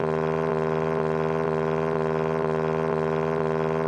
基础音效 " 发动机模型高转速3
描述：车辆发动机高转速噪音
Tag: 车辆中 高转速 空闲 汽车 发动机